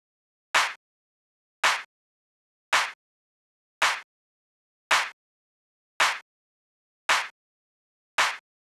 30 Clap.wav